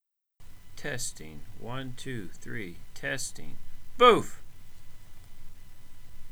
The volume is still quite low.